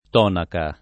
t0naka] s. f. — allòtropo pop. di tunica, pronunziato peraltro con -o- aperto fin dalle più antiche attestaz. e prob. da sempre, se già intorno al 600 d. C. il lat. classico tunica [t2nika] (con -u- breve) si era modificato in tonica e questa forma veniva accostata per falsa etimologia, da sant’Isidoro, a tonus «t(u)ono» — ant. o region. tonica [t0nika], talvolta usato fino al ’500 anche in sensi fig. dell’uso scient.